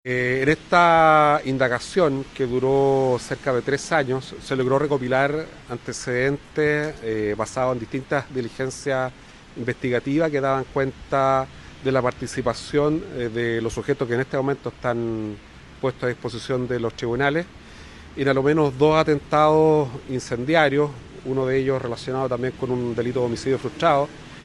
El fiscal regional subrogante, Alex Montesinos, señaló que las indagatorias permitieron reunir antecedentes que dan cuenta de la participación de los tres sujetos en los hechos, incluyendo un homicidio frustrado al agredir al trabajador mencionado.